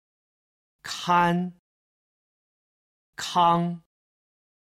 一声男性の「kan」と「kang」
11_kan_kang.mp3